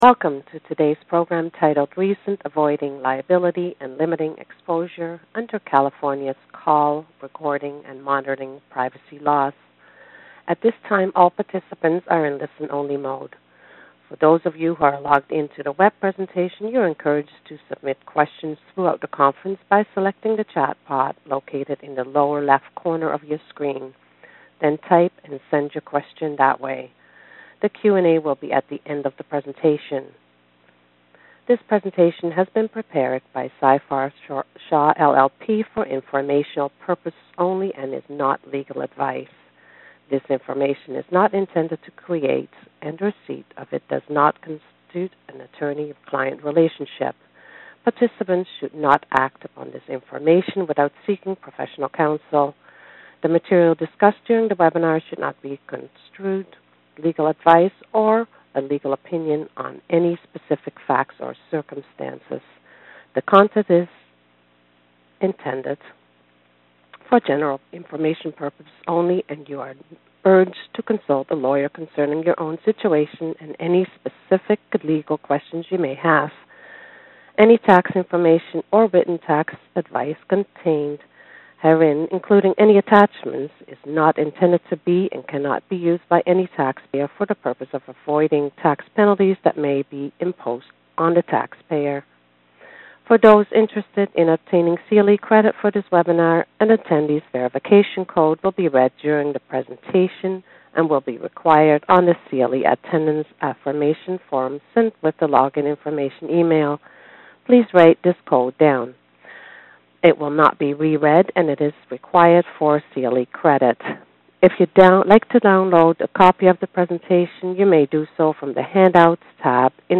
In Seyfarth’s first installment of its 2014 Class Action Webinar series, attorneys discussed how plaintiffs’ attorneys are increasingly filing class actions in…